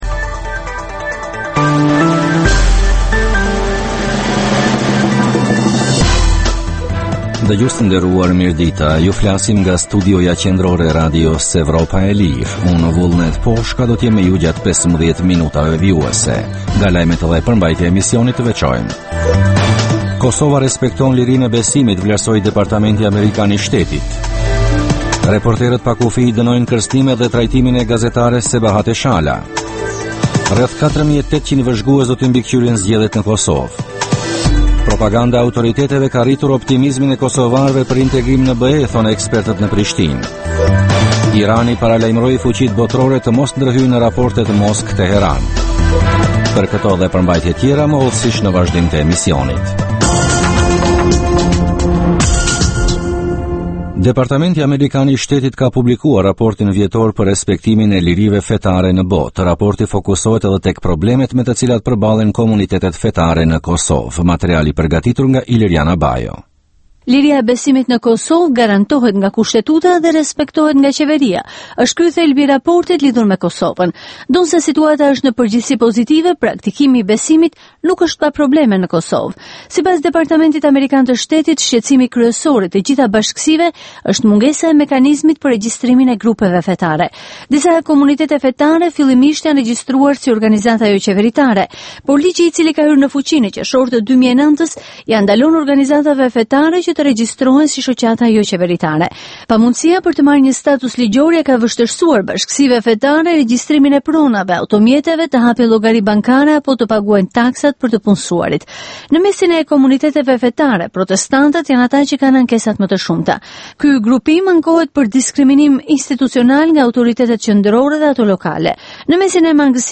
Emisioni i mesditës fillon me buletinin e lajmeve që kanë të bëjnë me zhvillimet e fundit në Kosovë, rajon dhe botë.
Emisioni i mesditës në të shumtën e rasteve sjellë artikuj nga shtypi perendimor, por edhe intervista me analistë të njohur ndërkombëtar kushtuar zhvillimeve në Kosovë dhe më gjërë.